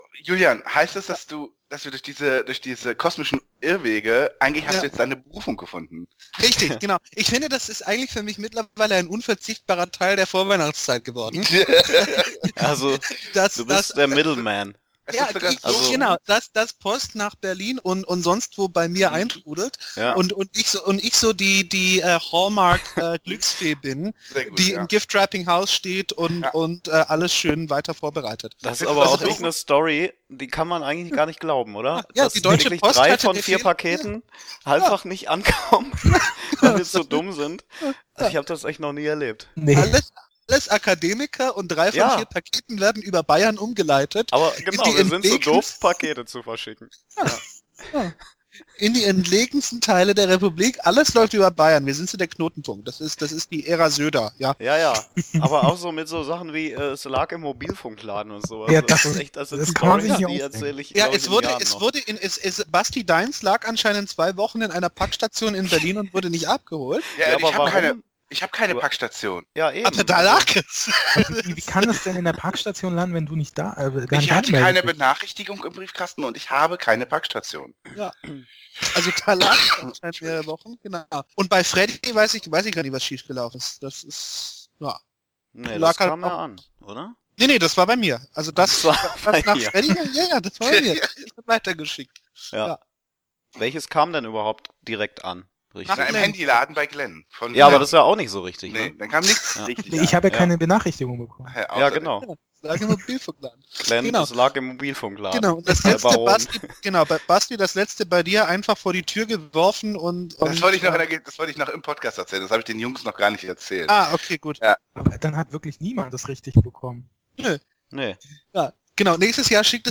Leider hatten wir ein paar Audioprobleme während der Aufnahme.